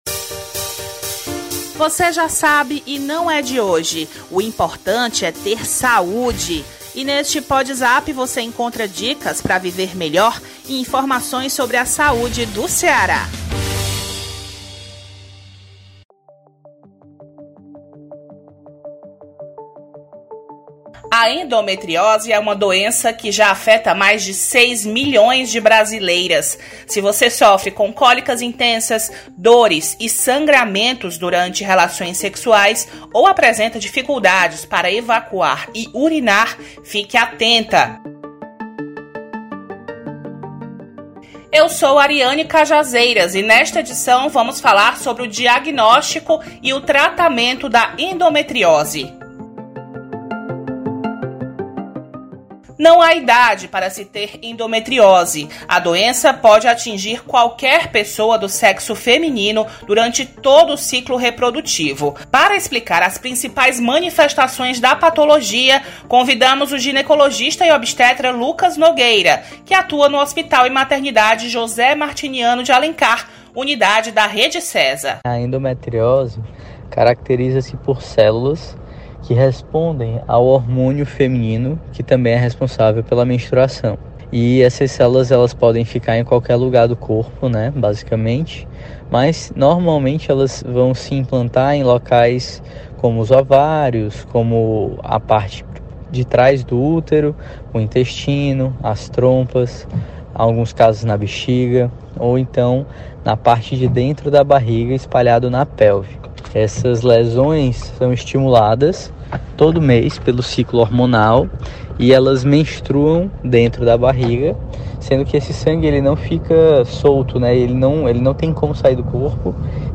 O médico também explica quando a cirurgia é necessária.